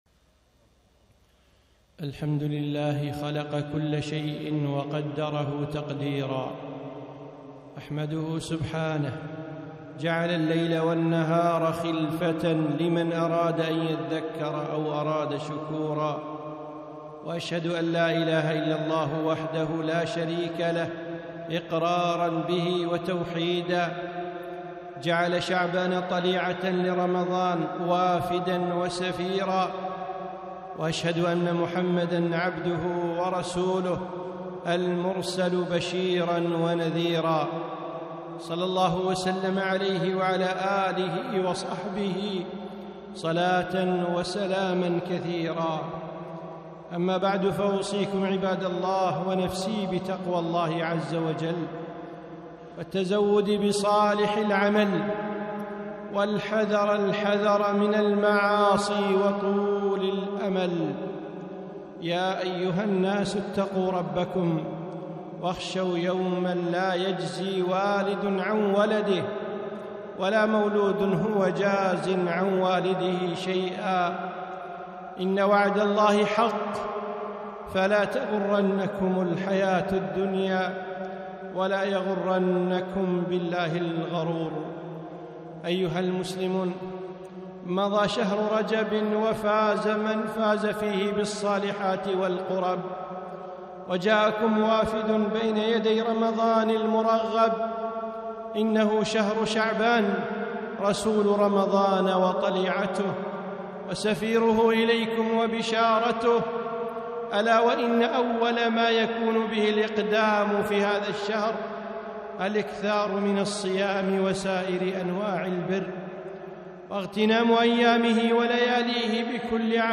خطبة - شعبان طليعة رمضان